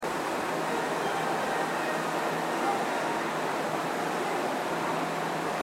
スピーカーはＴＯＡ型が設置されており音質も高音質です。
発車メロディーフルコーラスです。